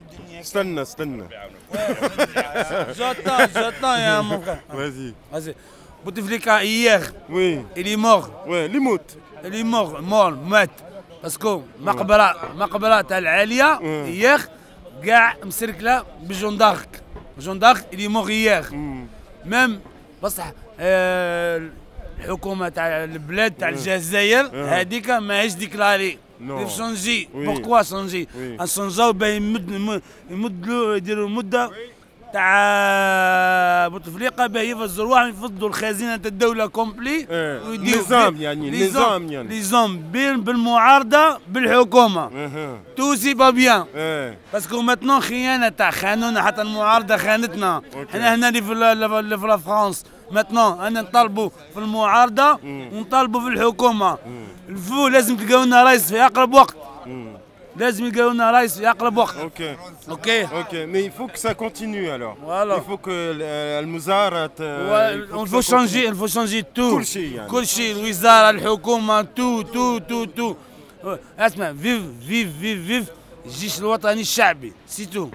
Selon lui, c’est le pouvoir au jeunes et rien d’autre. Il est accompagné à la voix d’un de ses frères de la survie du quotidien de Noailles…